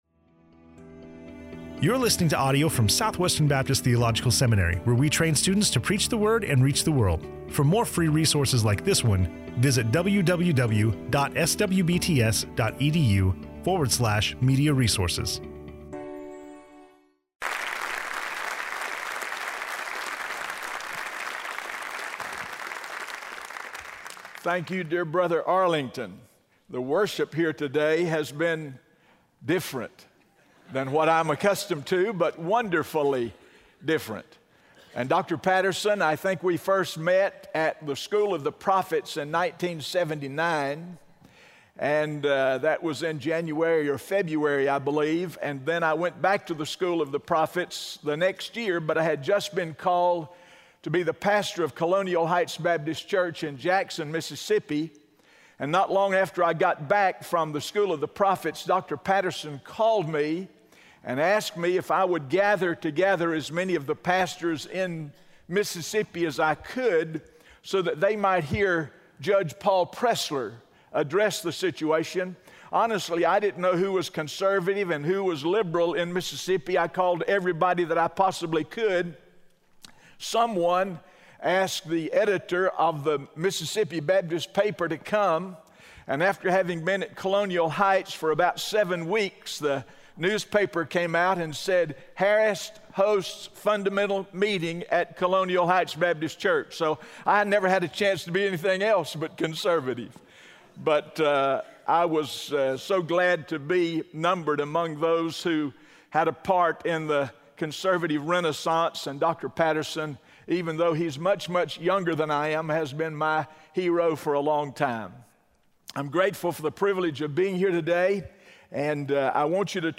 SWBTS Chapel